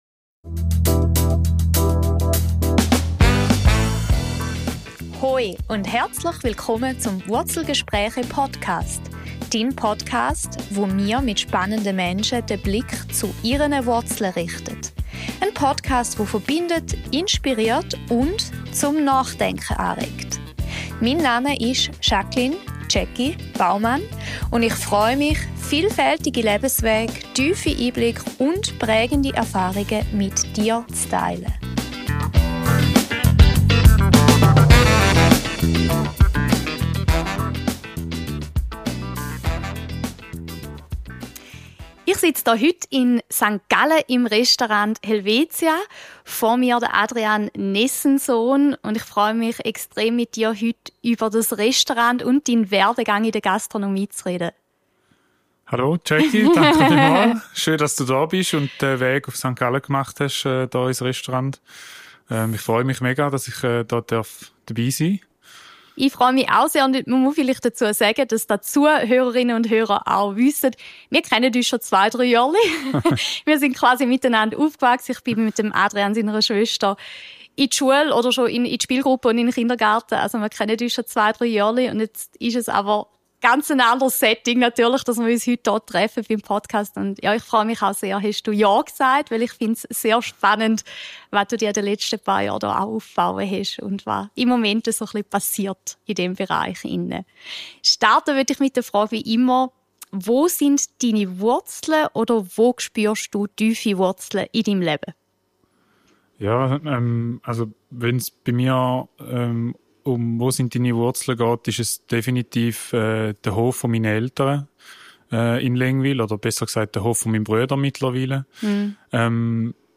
Ein inspirierendes Gespräch über Genuss, Verantwortung und die Balance zwischen Gastronomie, Kreativität und Leben.